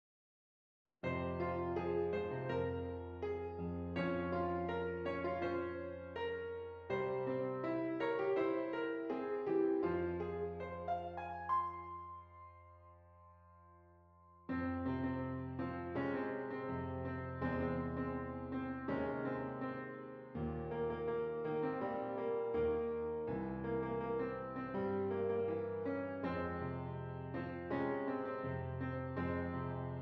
F Minor
Moderately